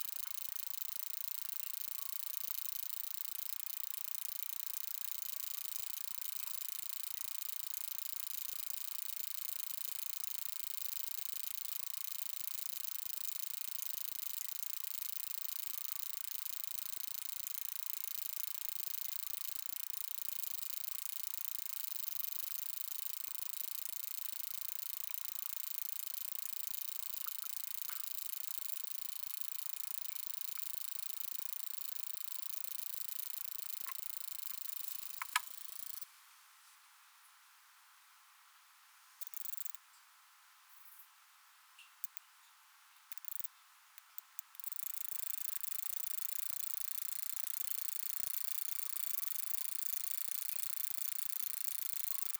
least katydid
least katydid Brachyinsara hemiptera Hebard 1939 map female female male male male 52 s of calling song and waveform; San Diego County, California; 24.7 °C.